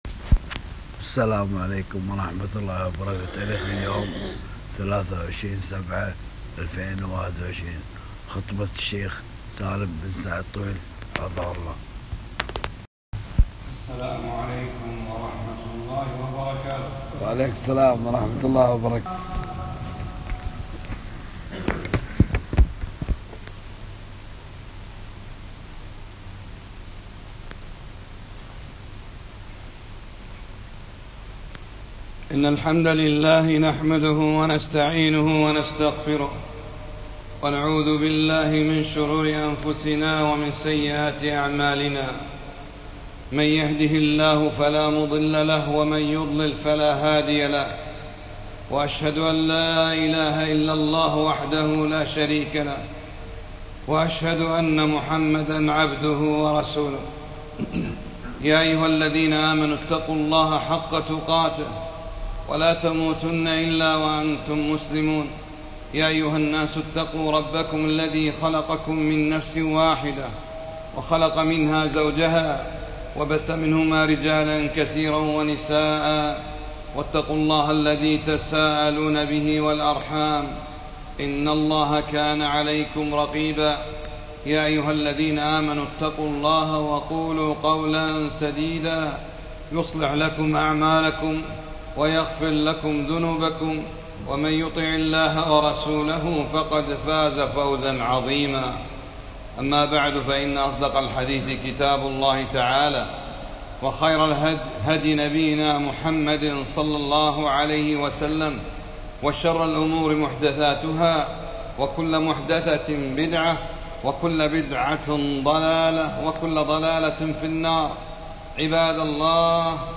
خطبة - سورة (ق) معاني عظيمة فاتعظوا يا عباد الله - دروس الكويت